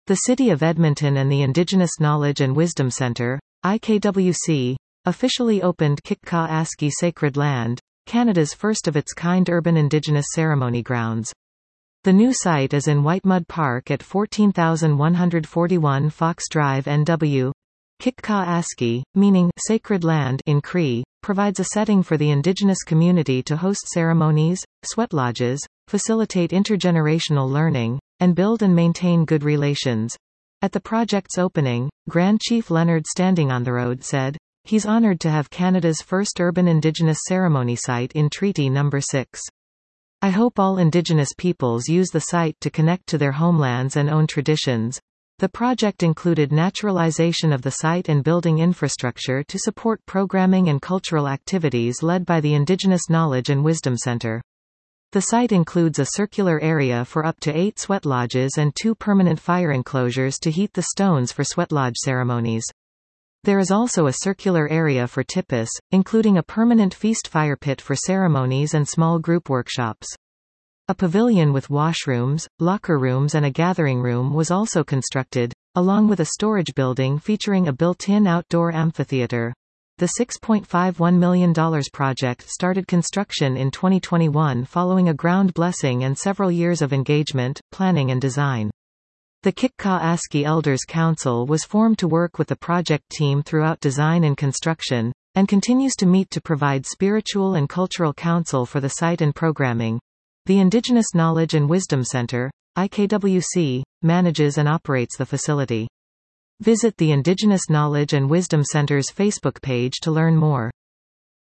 Listen to this article 00:01:51 The City of Edmonton and the Indigenous Knowledge & Wisdom Centre (IKWC) officially opened kihcihkaw askî-Sacred Land — Canada’s first-of-its-kind urban Indigenous ceremony grounds.